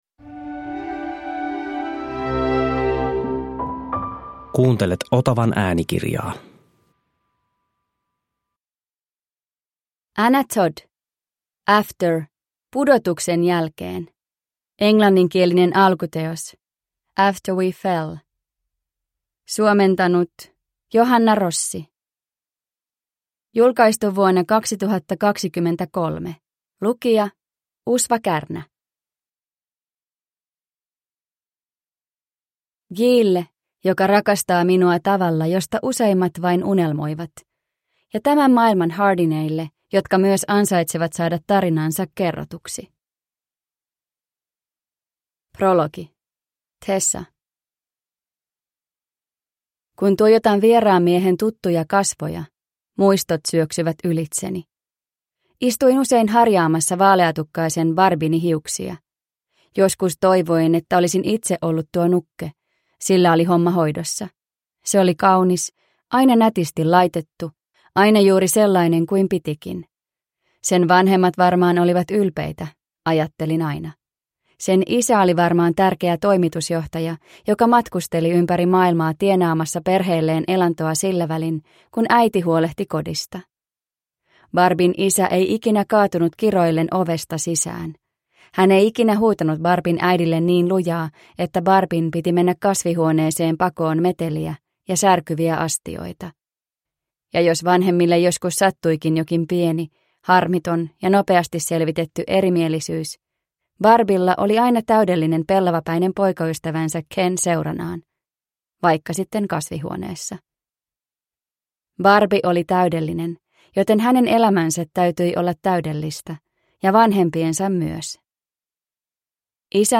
After - Pudotuksen jälkeen – Ljudbok